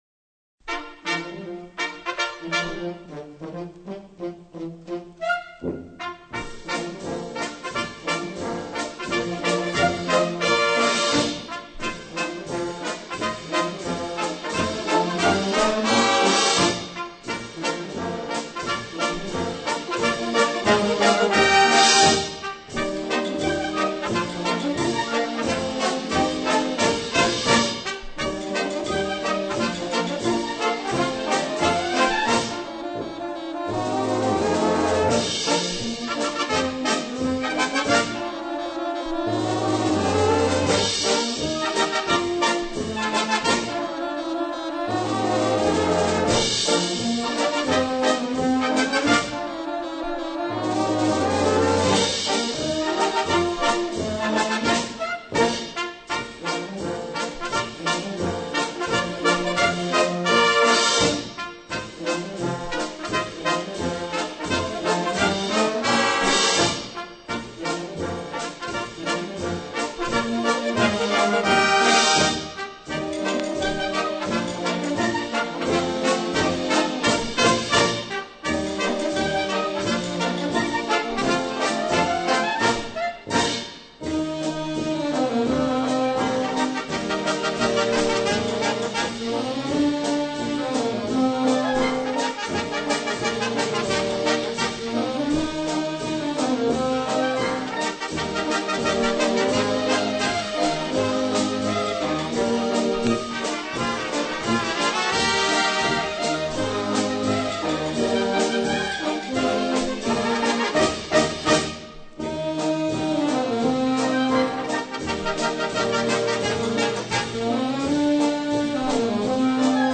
Gattung: Intermezzo Besetzung: Blasorchester